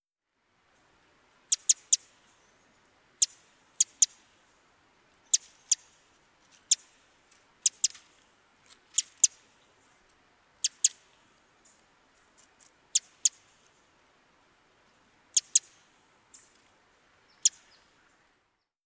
Birds and River
Bird2.wav